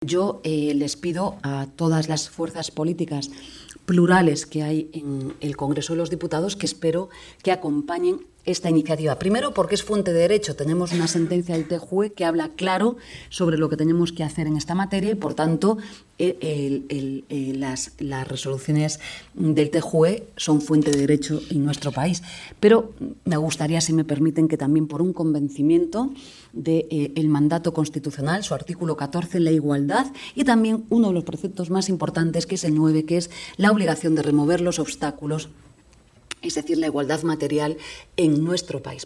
Yolanda Díaz enel acto de SERVIMEDIALa vicepresidenta segunda del Gobierno y ministra de Trabajo y Economía Social, Yolanda Díaz, destacó el 6 de marzo que la reforma del despido por incapacidad derivada de una discapacidad sobrevenida, que inicia ya su procedimiento, se produce porque “nuestro país tiene una deuda con las personas con discapacidad que se debe corregir", dijo formato MP3 audio(0,30 MB)Así lo manifestó en un diálogo organizado por Servimedia y la Unión de Profesionales y Trabajadores Autónomos (UPTA) bajo el título ‘Los retos y el futuro del trabajo autónomo’, celebrada en el marco del 35º aniversario de Servimedia.